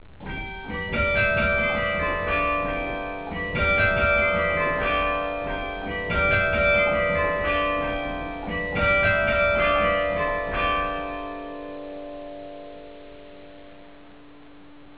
Le carillon du beffroi de Bergues - Les Ritournelles - 1er Quart
C'était la ritournelle la plus courte.